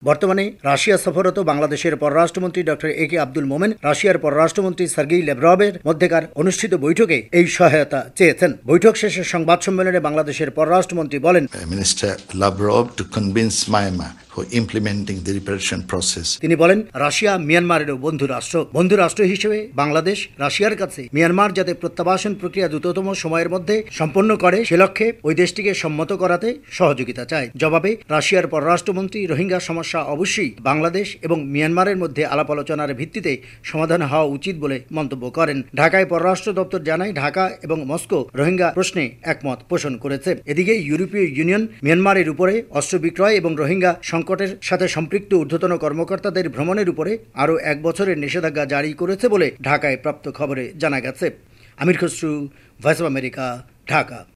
রিপোর্ট।